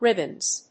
発音記号・読み方
/ˈrɪbʌnz(米国英語)/